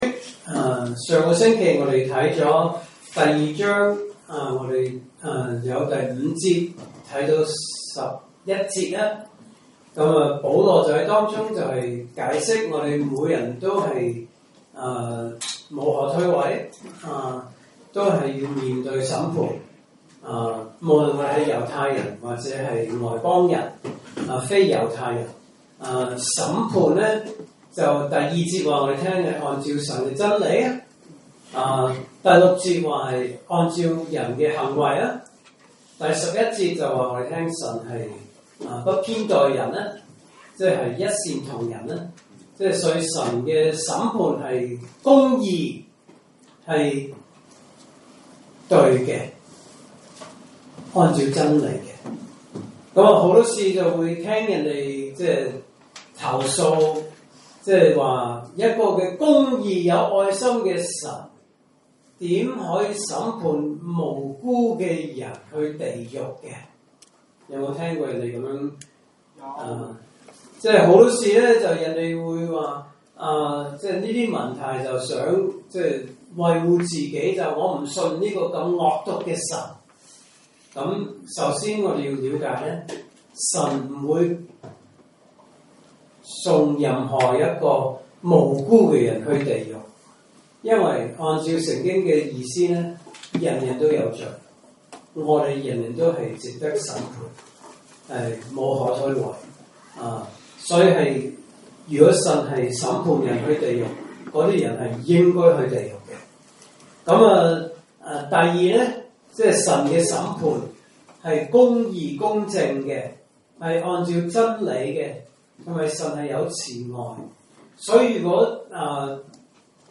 來自講道系列 "查經班：羅馬書"